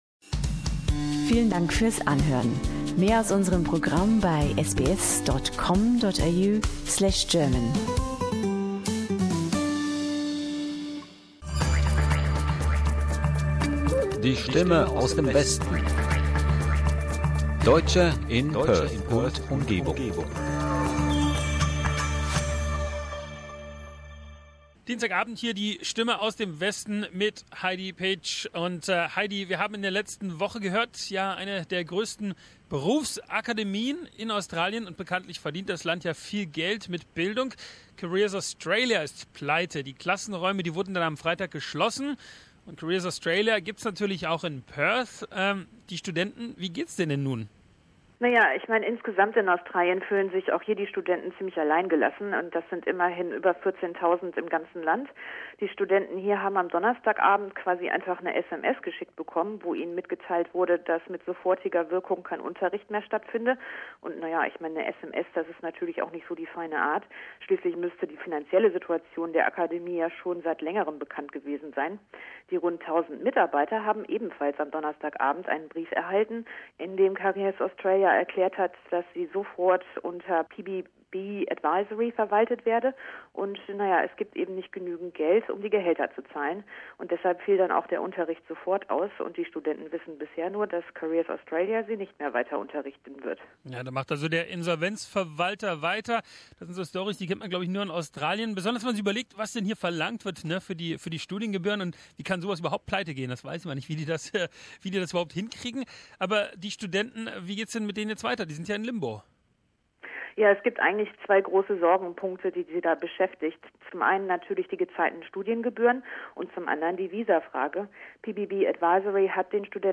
im wöchentlichen Kollegengespräch